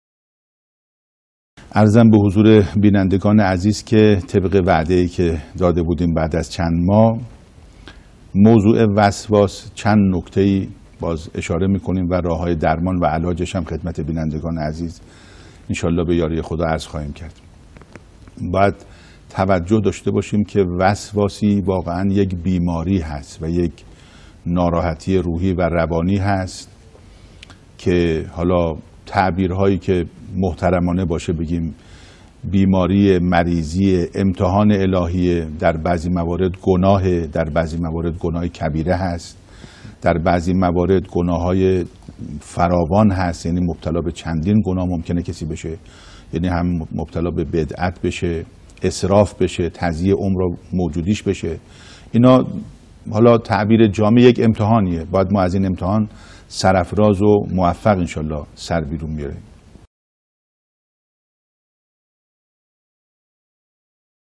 سخنرانی | بیماری وسواس یک گناه و یا یک امتحان الهی است